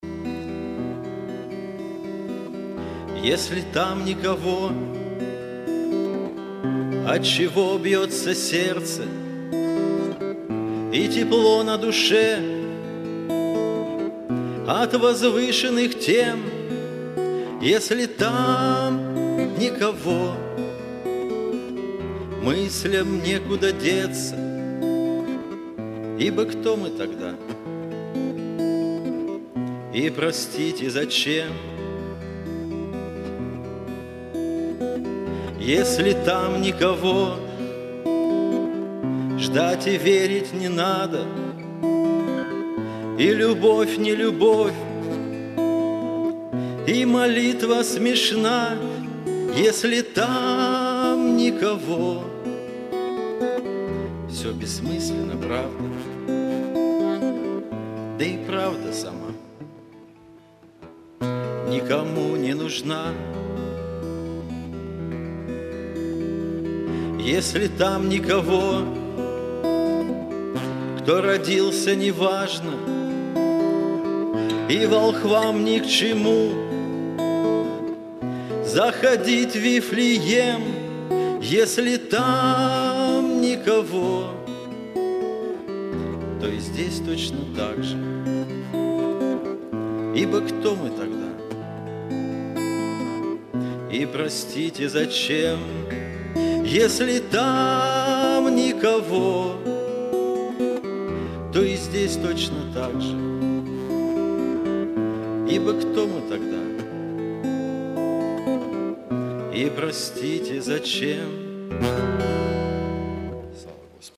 Богослужение 04.01.2023
Пение